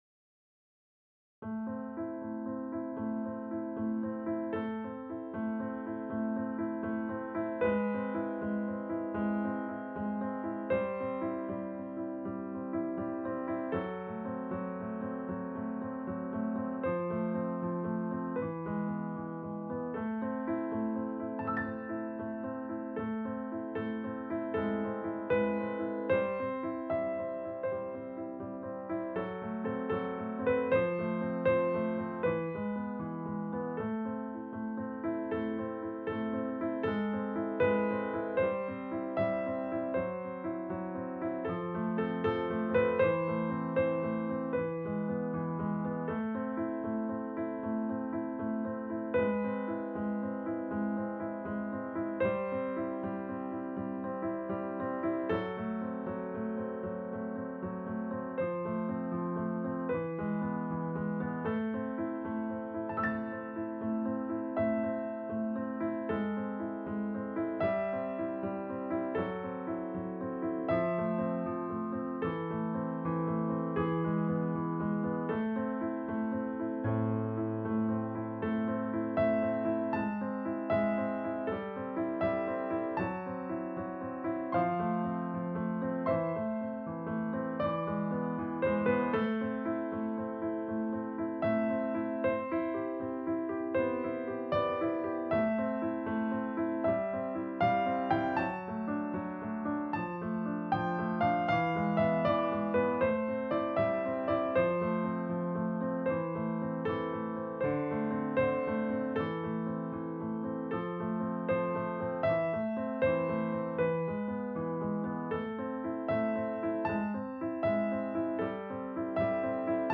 A triplet pattern is used throughout.
Piano Accompaniment mp3